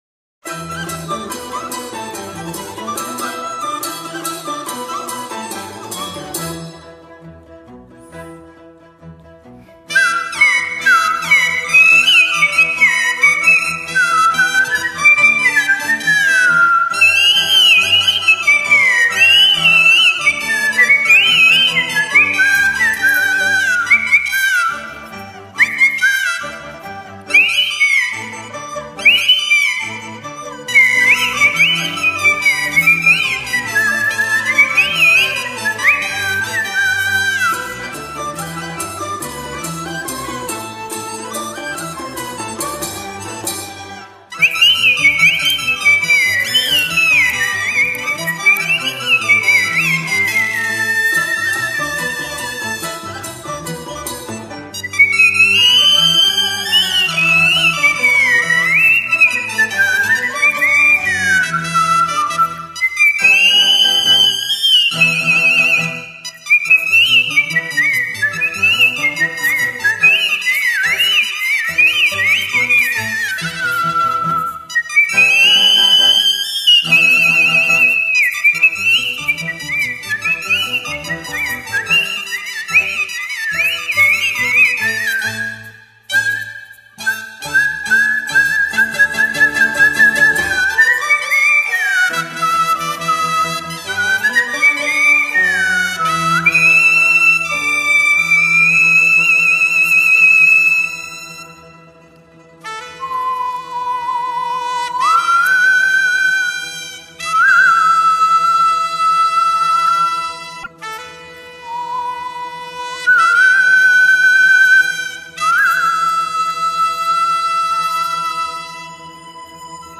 吹得很传神，很入戏，既轻盈又欢快，乡土气息很浓，气息把握也相当好，唢呐都相形见拙了。